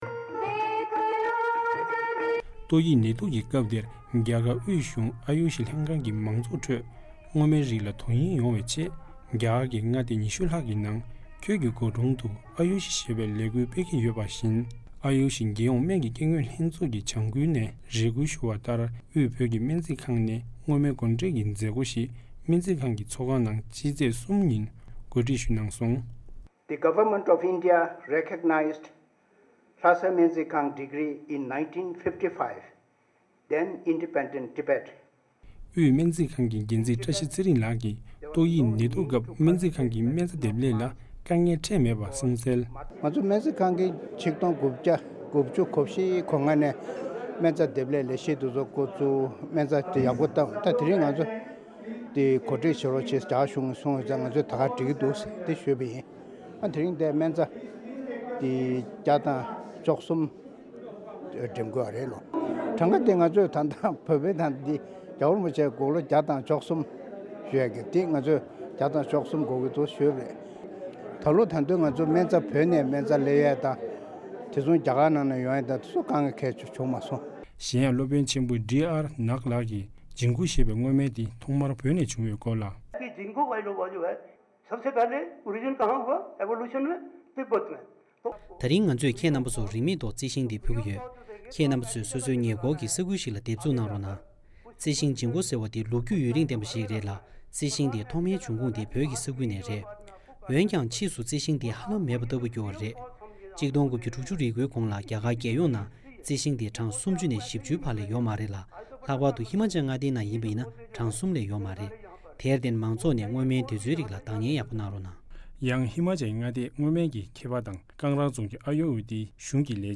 ༄༅།། རྒྱ་གར་དབུས་གཞུང་ཨ་ཡུ་ཤ་ལྷན་ཁང་གི་ལས་གཞིའི་འོག་དབུས་སྨན་རྩིས་ཁང་གིས་སྔོ་སྨན་བགོ་འགྲེམ་བྱེད་ཀྱི་ཡོད་པའི་སྐོར། བཞུགས་སྒར་རྡ་རམ་ས་ལ་ནས་ཨ་རིའི་རླུང་འཕྲིན་ཁང་གི་གསར་འགོད་པ